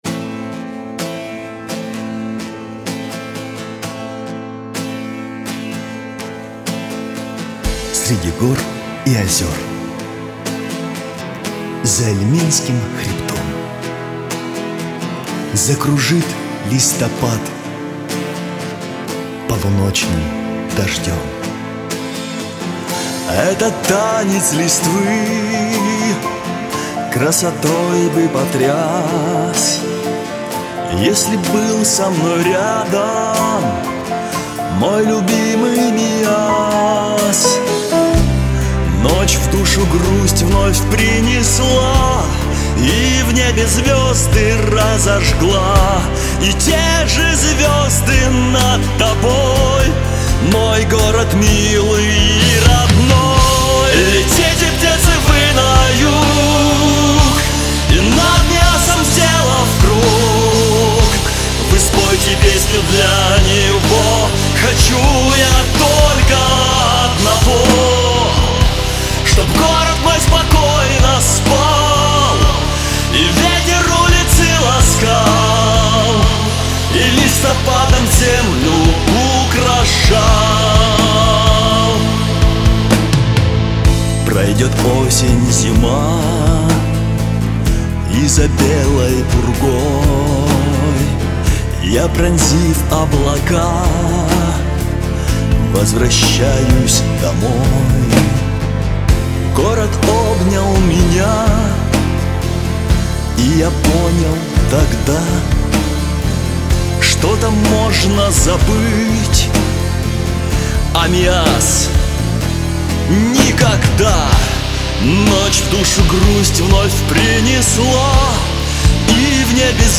НК Рок-группа «Орион» ДК «Динамо»